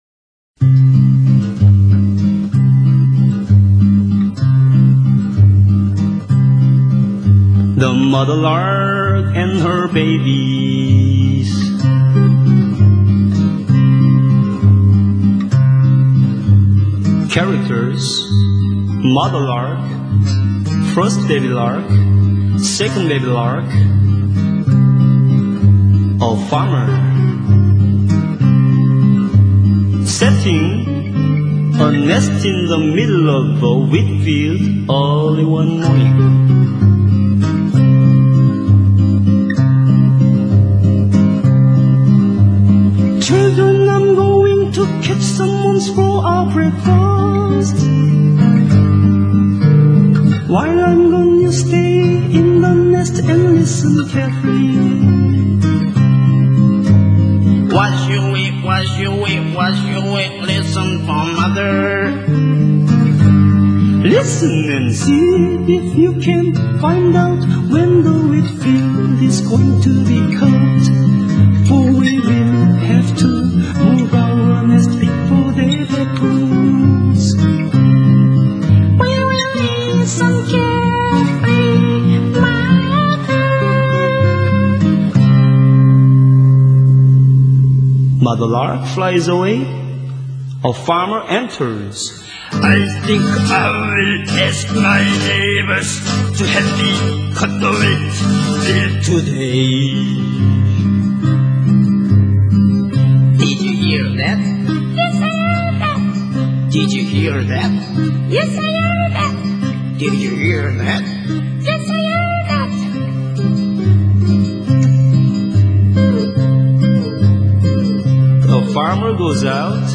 ♬ 음악/Jazz